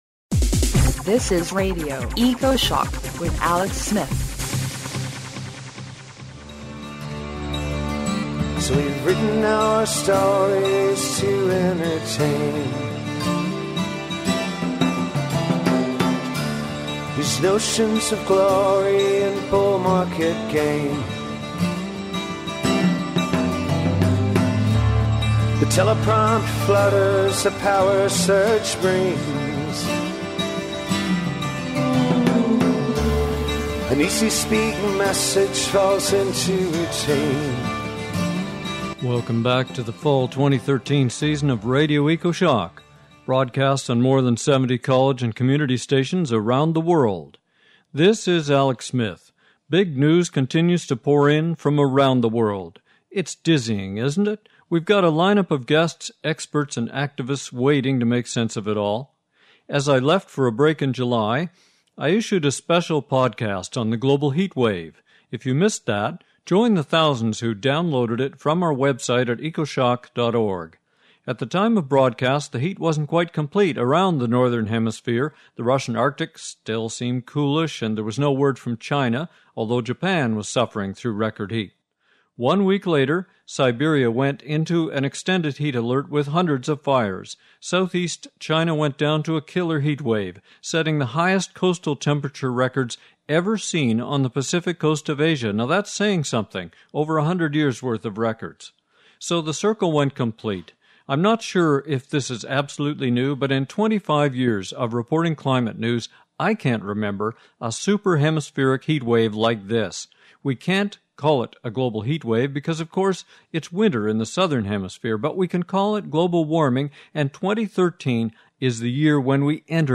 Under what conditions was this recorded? Welcome back to the Fall 2013 season of Radio Ecoshock, broadcast on more than 70 college and community stations around the world.